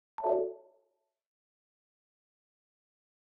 Download UI Off sound effect for free.